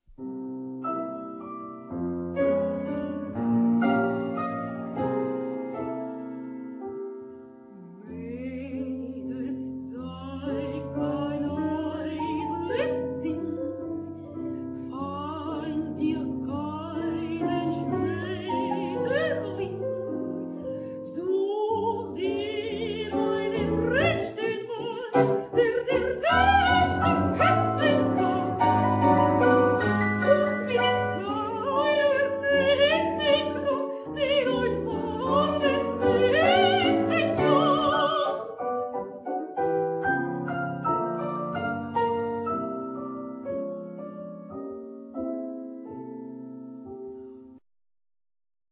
Soprano
Piano